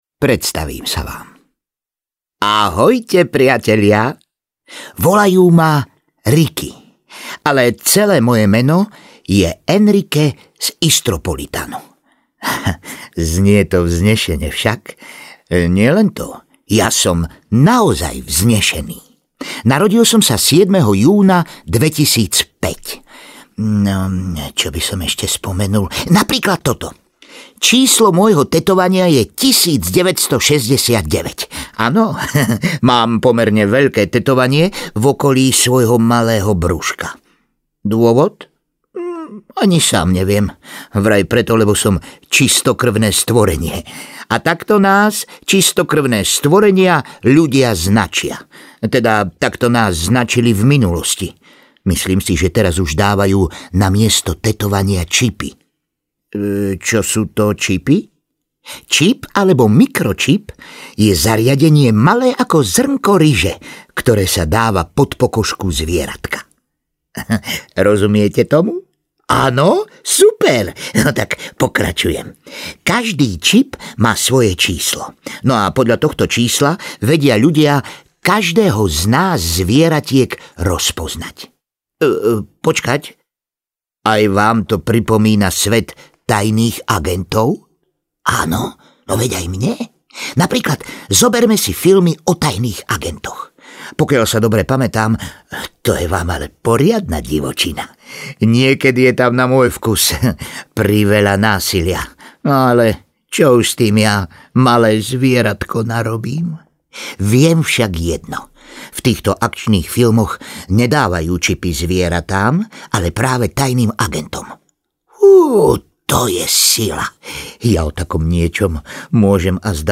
Ricki (komplet) audiokniha
Ukázka z knihy